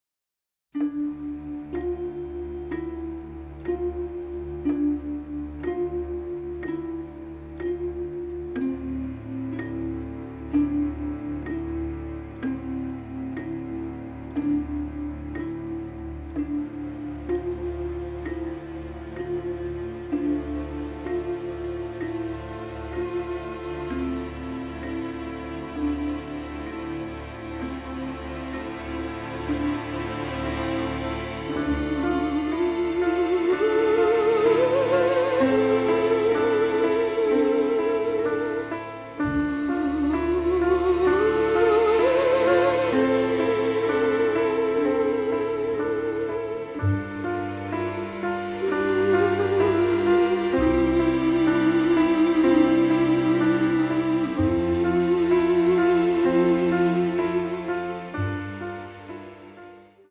and one of its most beautiful and heartbreaking themes